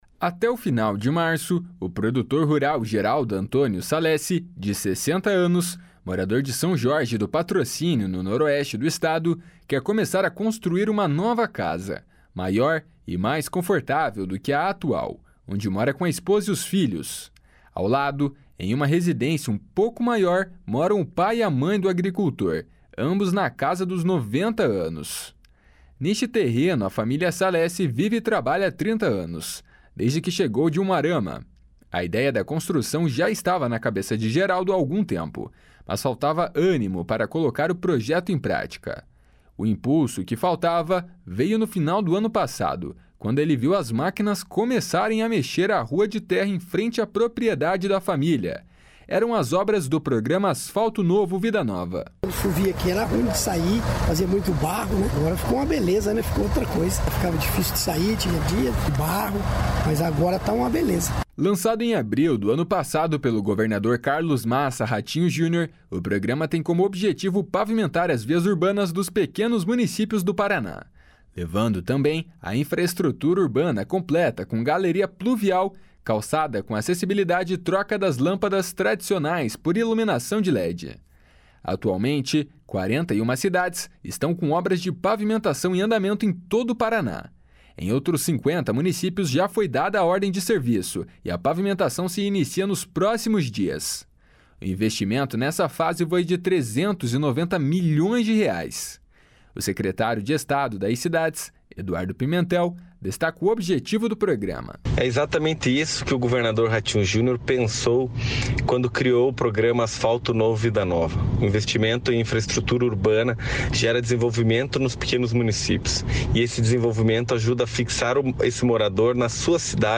OBRAS DO ASFALTO NOVO VIDA NOVA.mp3